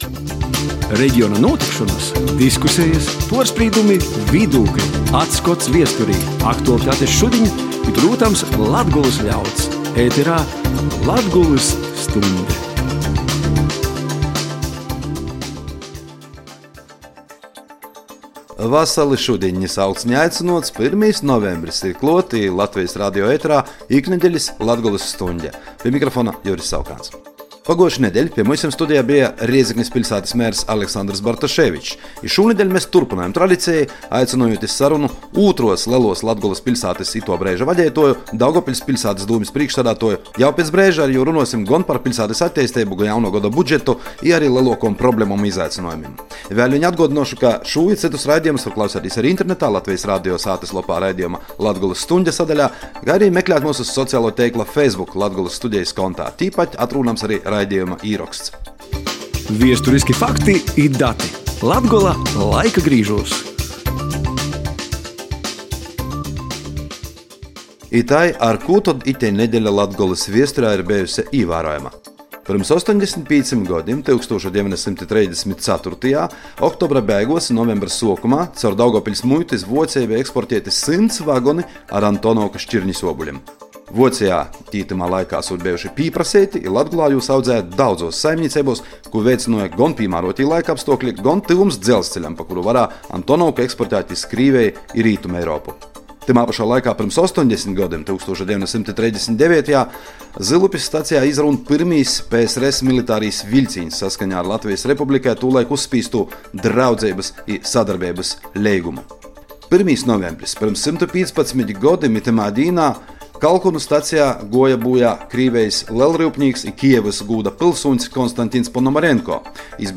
Intervija ar Daugavpils domes priekšsēdētāju Andreju Elksniņu